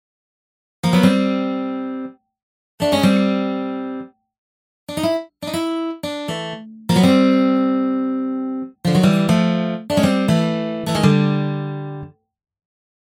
The resulting sound
is quite recognizable as a sliding sound, but it does not sound realistic at all, does it?